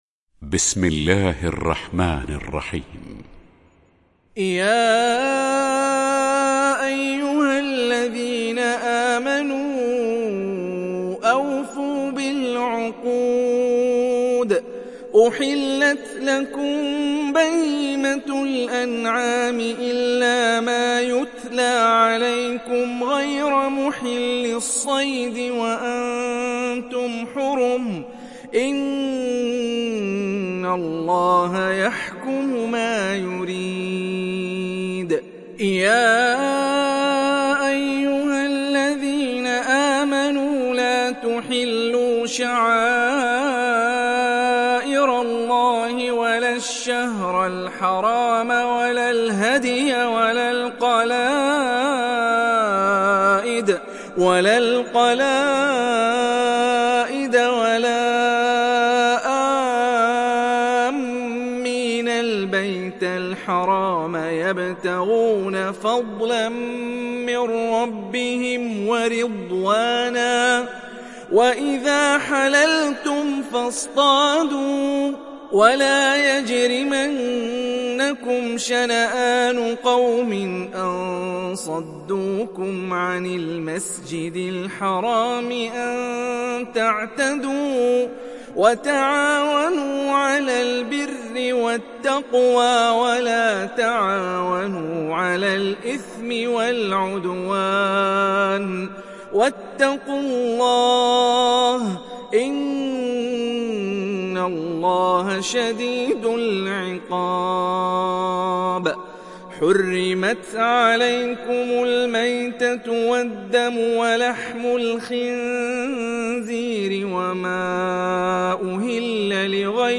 সূরা আল-মায়েদাহ্ ডাউনলোড mp3 Hani Rifai উপন্যাস Hafs থেকে Asim, ডাউনলোড করুন এবং কুরআন শুনুন mp3 সম্পূর্ণ সরাসরি লিঙ্ক